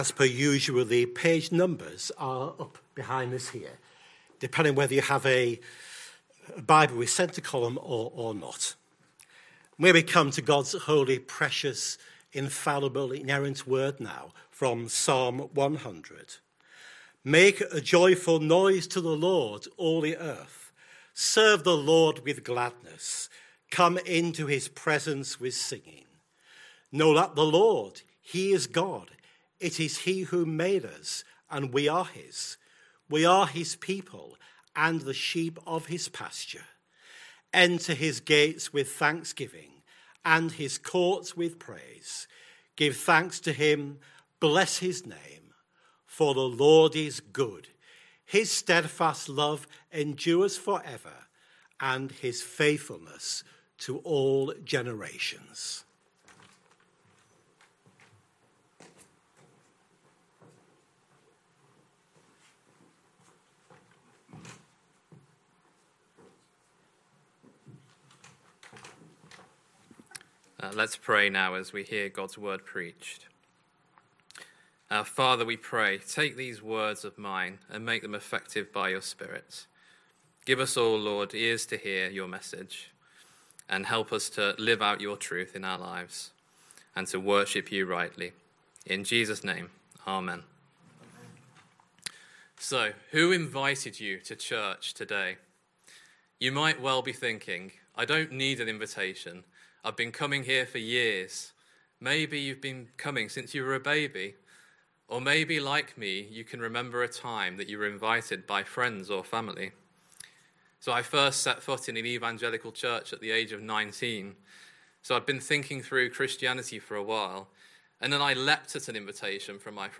Christ Church Sermon Archive